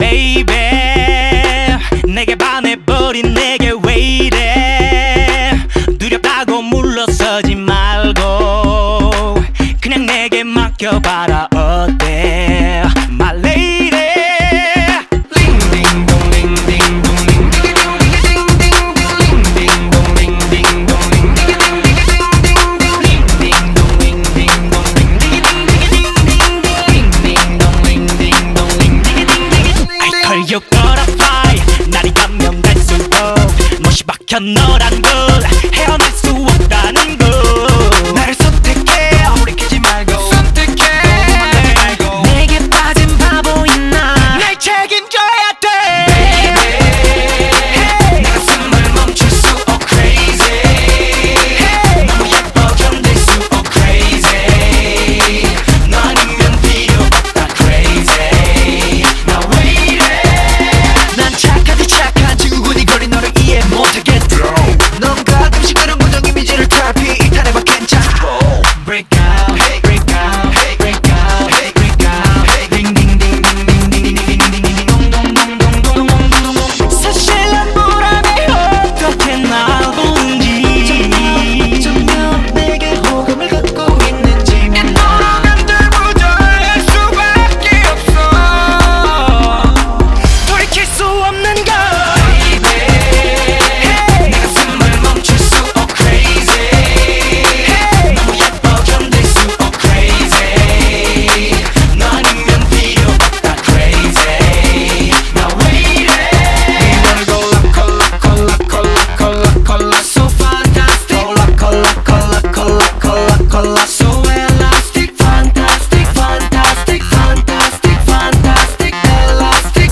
BPM125
Anyway, Iconic K-Pop song is iconic.